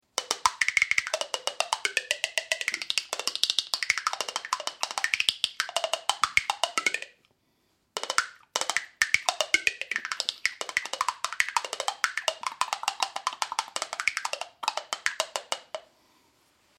Rencontre improbable de la cuillère canadienne et de la guimbarde, il se joue comme une guimbarde, la bouche devant laquelle le rakatak est posé permet d’amplifier et de moduler le son.
Instrument en roseau et bambou